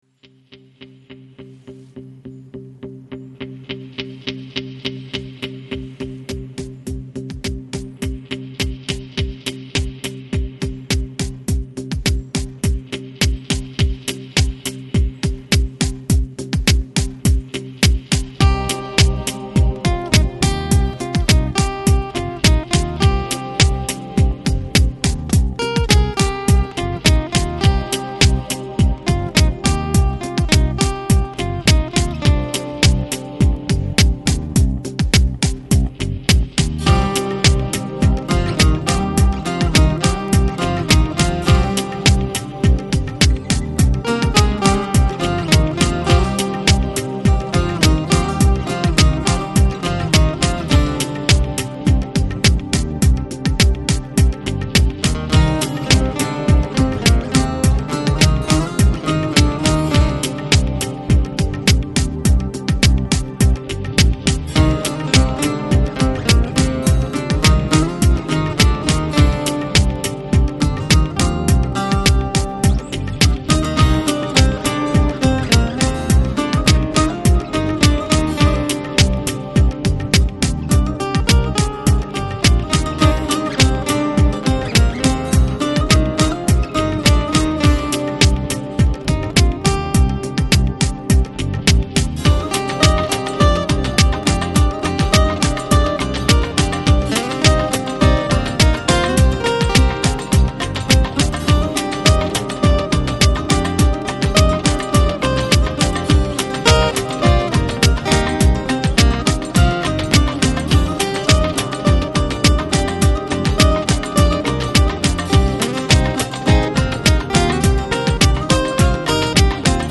Жанр: Electronic, Lounge, Downtempo, Chill Out, Balearic